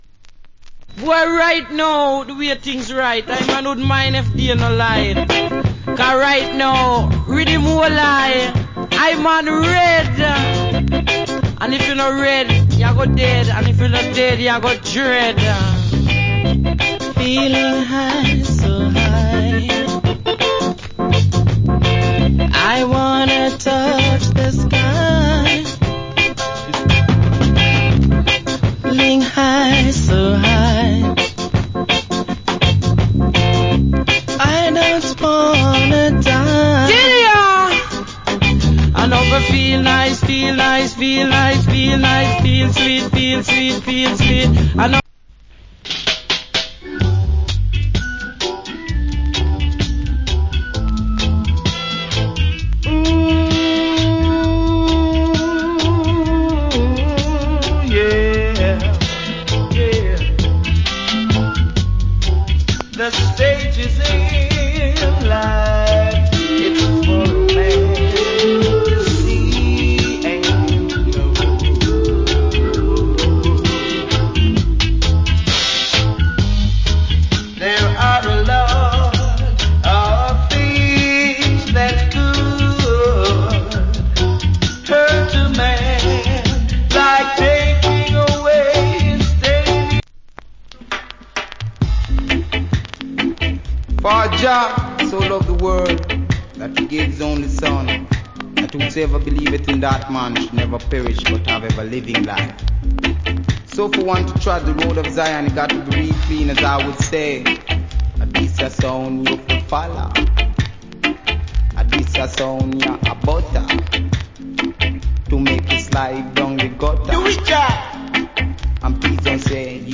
Nice Roots Rock & Reggae, DJ.